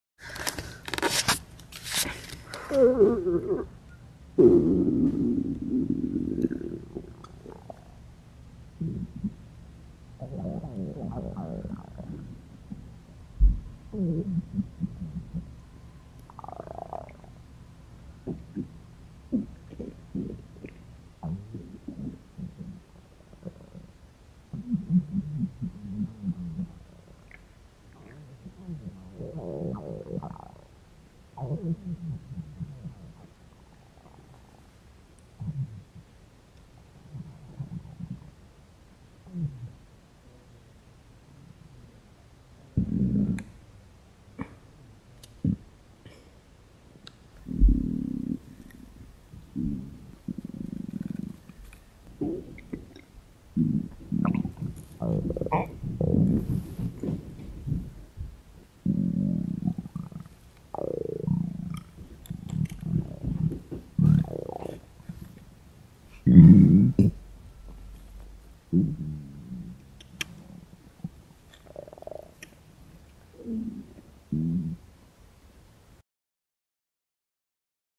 Звуки урчания в животе
На этой странице собраны натуральные звуки урчания в животе, которые можно скачать или слушать онлайн.
Разные вариации звуков: от легкого бурчания до интенсивного урчания после еды.
Урчание в животе звуки желудка